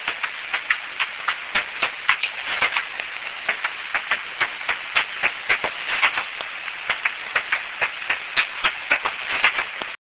En vol, la sérotine commune émet une assez lente succession irrégulière de cris d'écholocation en fréquence modulée aplatie aux alentours de 27 kHz, ce qui donne à cette fréquence au détecteur ultrasonique de chauves-souris des "tchac tchac" sur un rythme irrégulier, comme vous pouvez l'entendre sur ces deux enregistrements:
enregistrement des cris d'écholocation d'une sérotine commune (Pettersson D200 réglé sur 28 kHz - 04/09/2008 - Watermael-Boitsfort, Belgique).